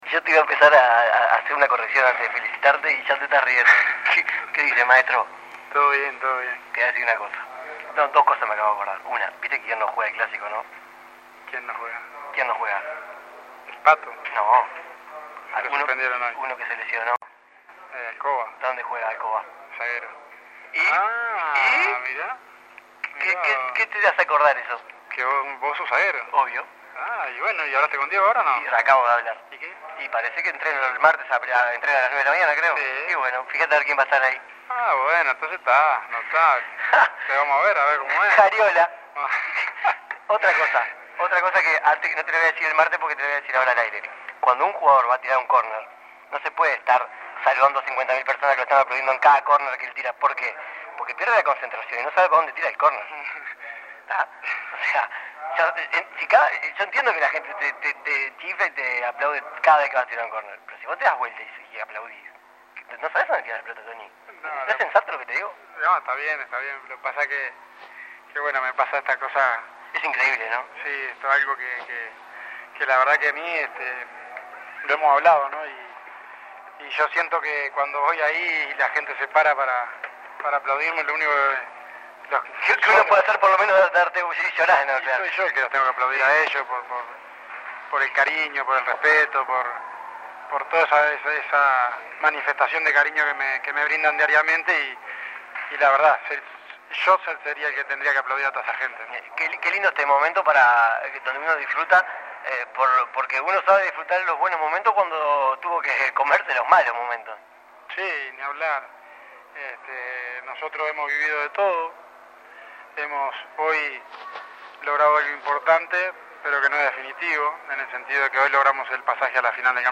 El capitán de Peñarol, Antonio Pacheco, habló con 13 a 0 despues de vencer a Fénix y obtener el Torneo Clausura. Analizó el torneo que realizó Peñarol, el clásico de la próxima fecha y los aplausos que recibe de la hinchada.